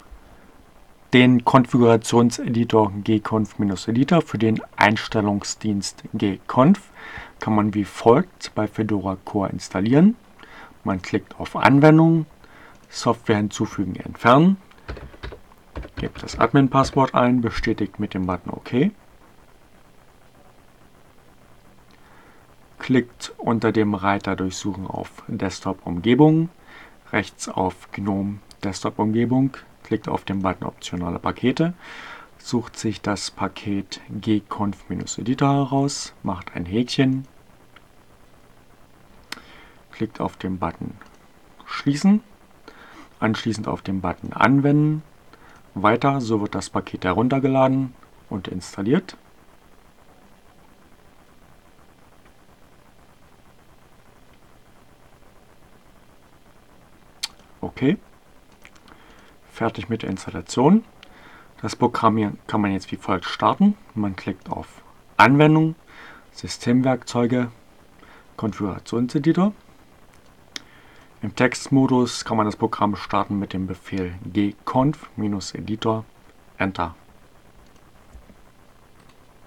Tags: Anfänger, CC by-sa, Fedora Core, gconf, gconf-editor, Gnome, Linux, Ogg Theora, ohne Musik, screencast, short